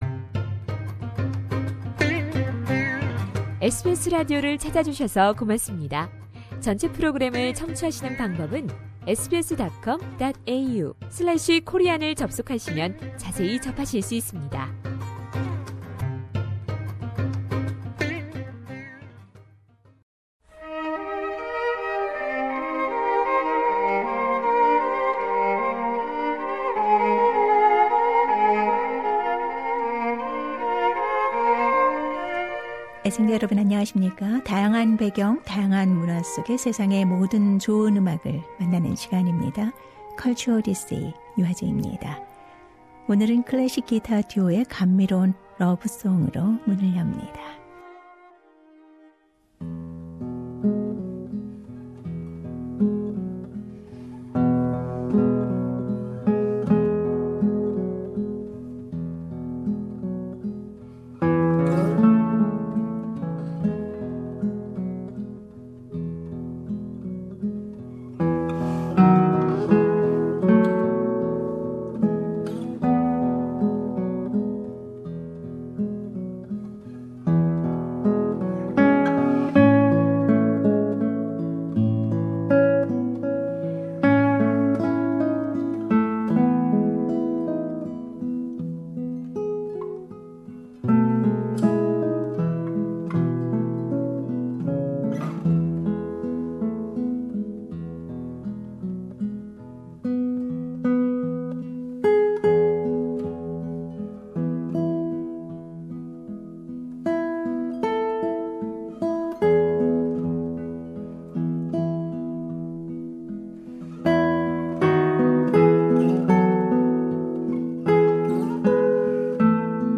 클래식 기타 오페라하우스 공연
Classical Guitarist